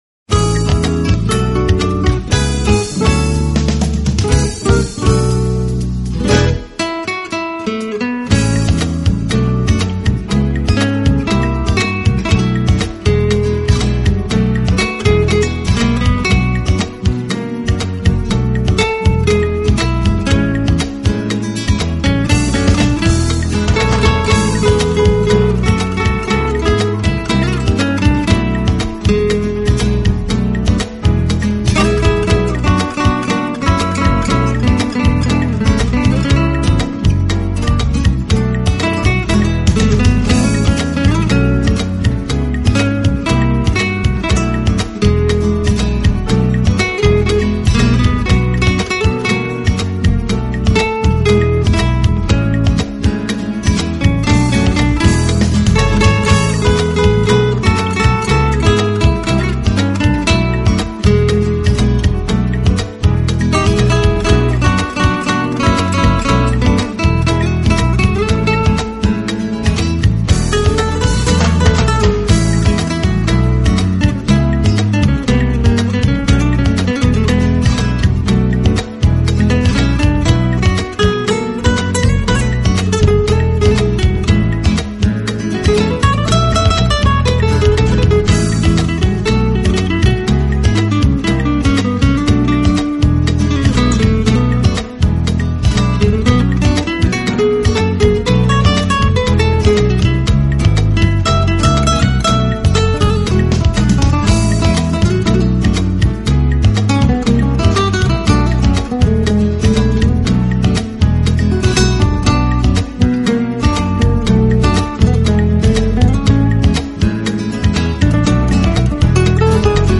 饰，完完全全是吉他散发出来的魅力。